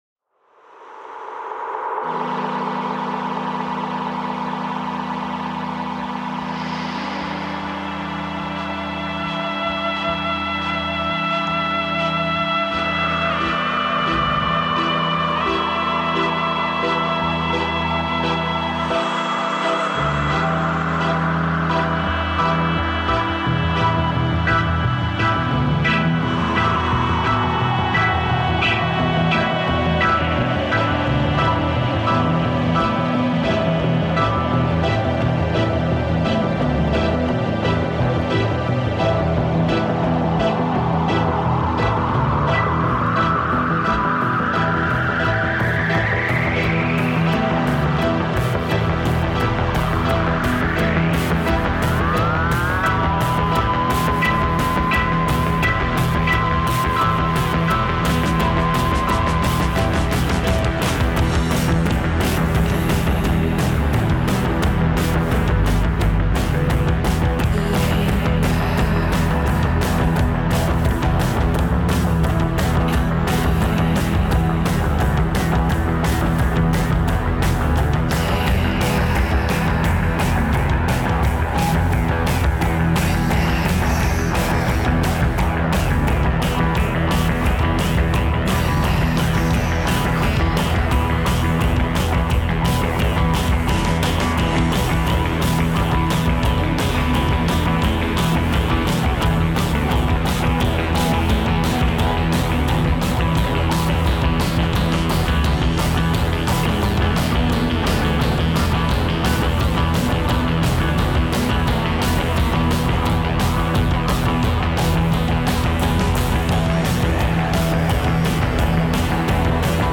concession-free kosmische rock.
more controlled and cathartic than ever before